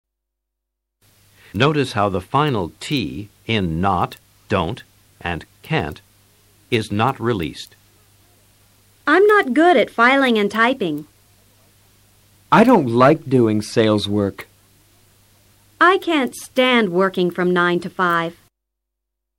Escucha a los profesores e intenta IMITAR SU PRONUNCIACION.
Notice how the final t in not, don't, and can't is not released.
Observa la ausencia de sonido en la t final de not, don't y can't.